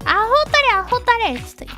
Worms speechbanks
Stupid.wav